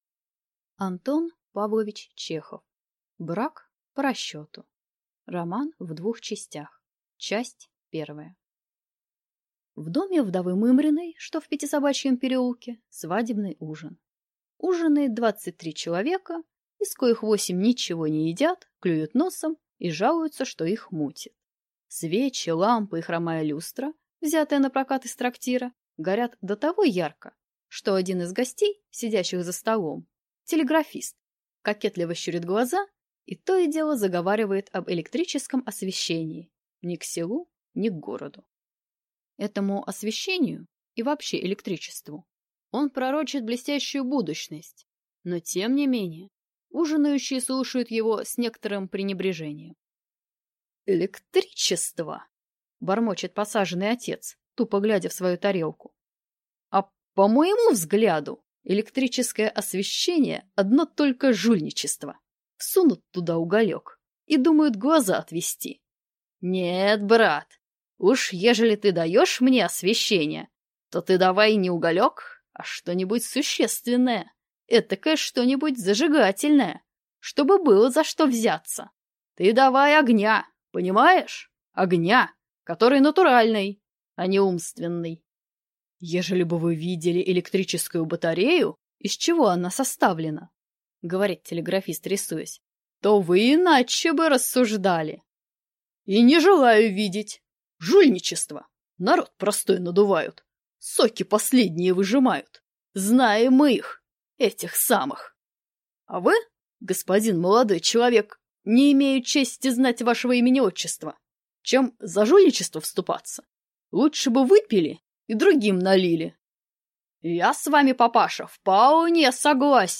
Аудиокнига Брак по расчету | Библиотека аудиокниг